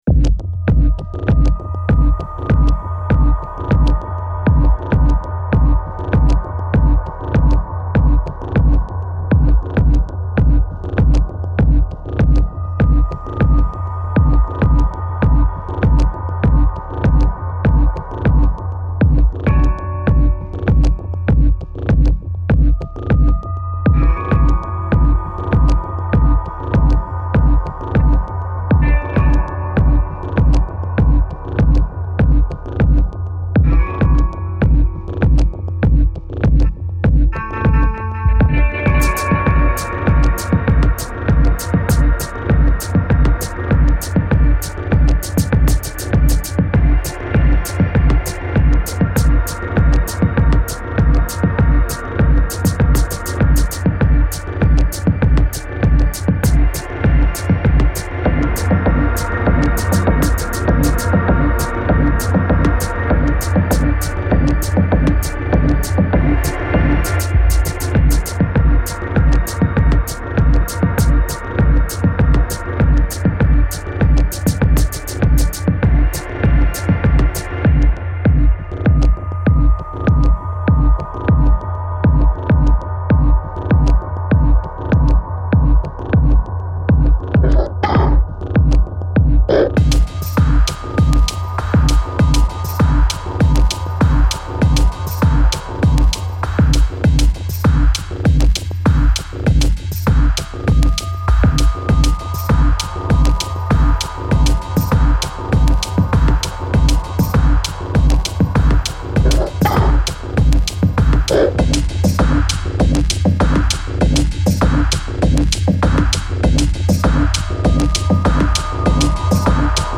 Techno その他のおすすめレコード